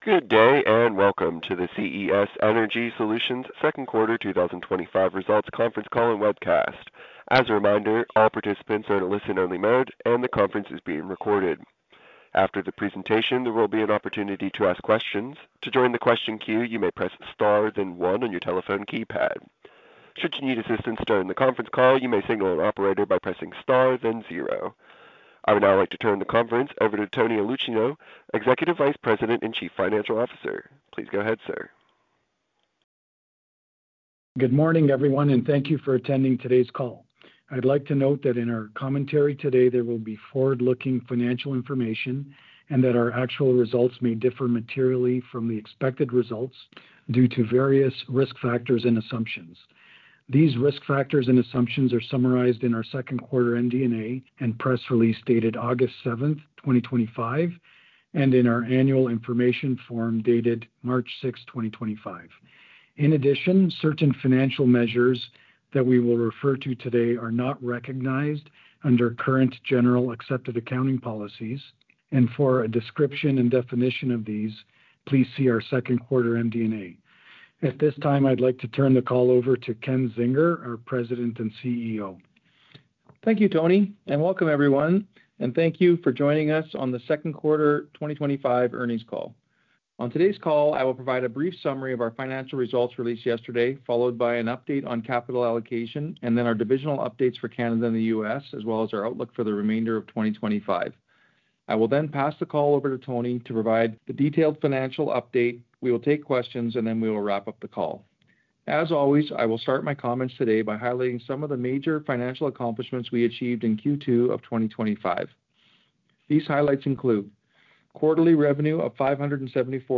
Q4 2025 Earnings Call - Match 11, 2026